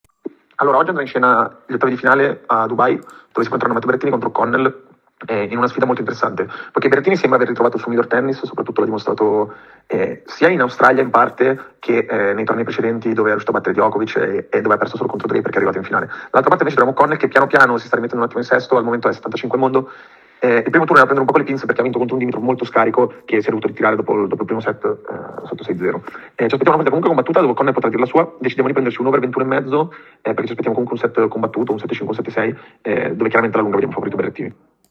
In questa audio analisi il nostro esperto di scommesse sul Tennis